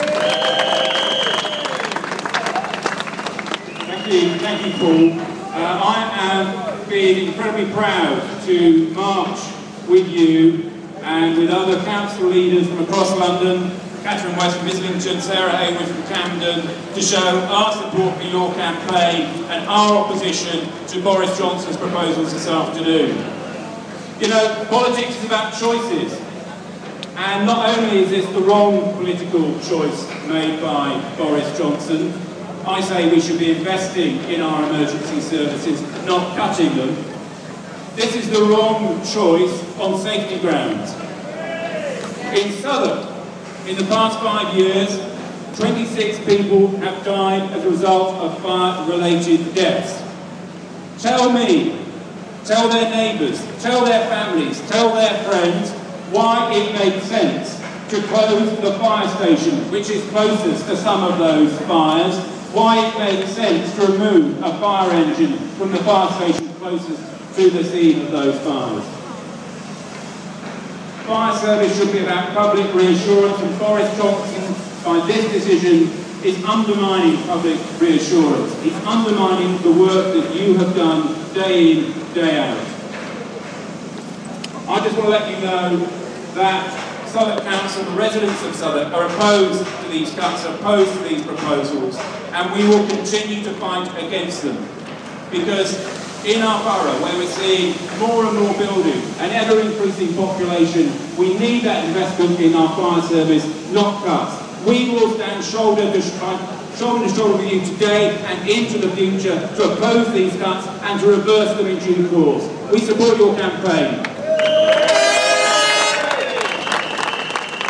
Cllr Peter John speech at anti fire cuts demo
Union Street SE1